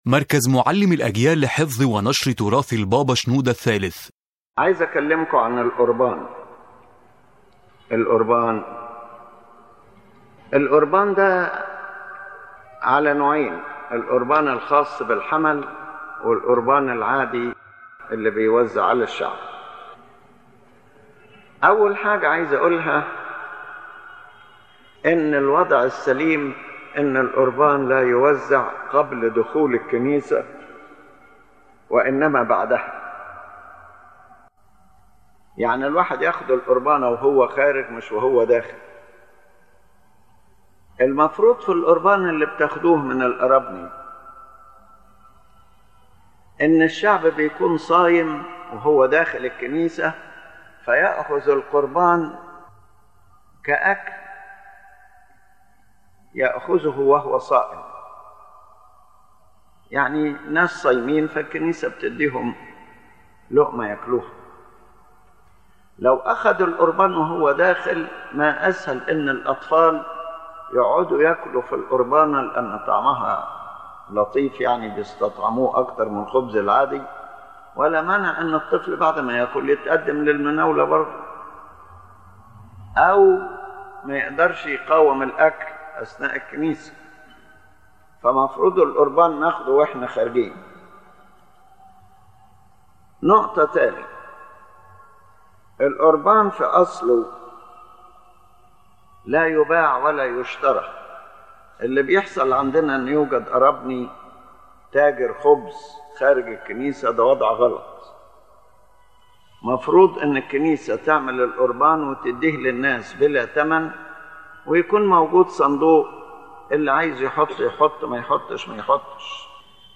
Lecture summary: The Eucharist )